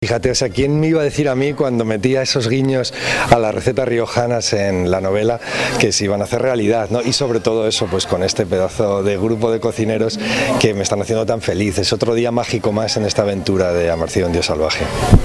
no ocultaba su emoción formato MP3 audio(0,29 MB) por la vivencia de lo que denominó “un día mágico” en torno a su creación.